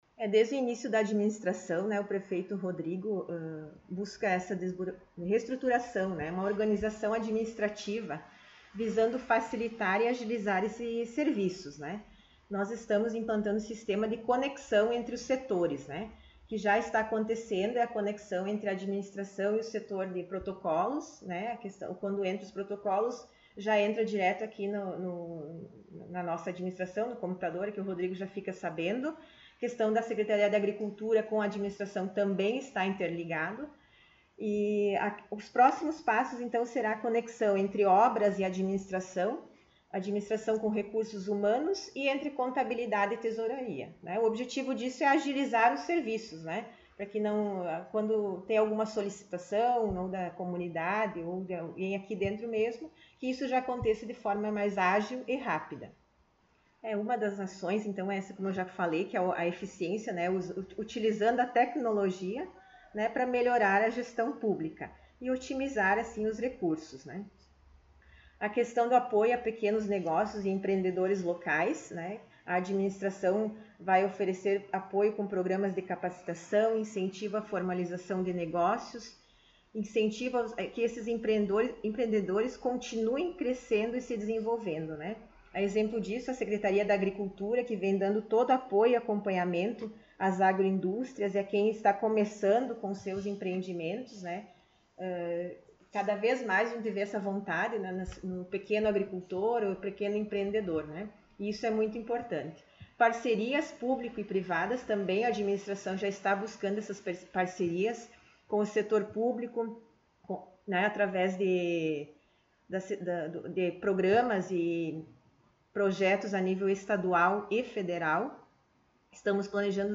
Secretária Municipal de Administração e Fazenda concedeu entrevista
O Colorado em Foco esteve na Prefeitura, na sala da secretária, para sabermos um pouco mais da situação econômica do município e outros assuntos.